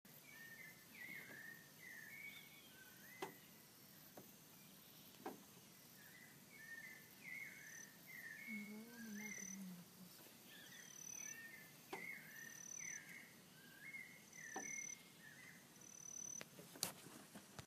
White-necked Thrush (Turdus albicollis)
Life Stage: Adult
Location or protected area: Reserva Privada y Ecolodge Surucuá
Condition: Wild
Certainty: Recorded vocal